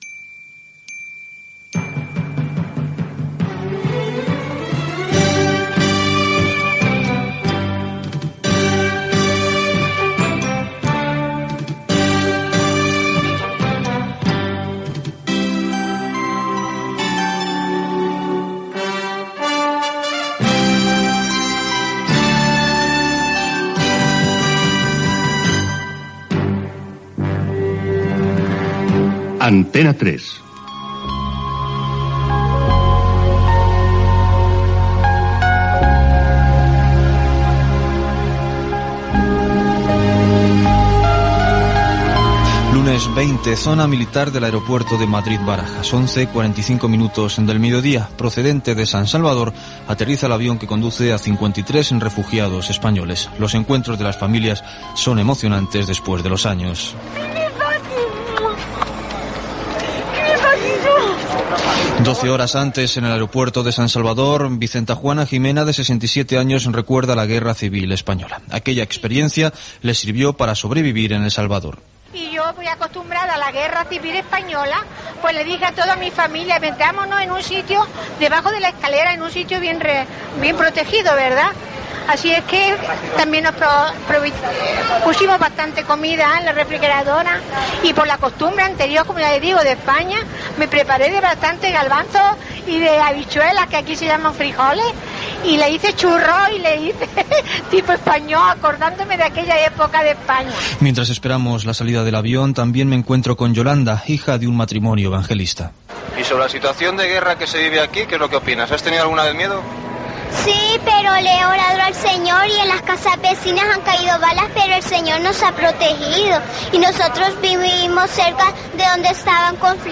Sintonia de l'emissora, identificació, reportatge sobre la la guerra civil de El Salvador Gènere radiofònic Informatiu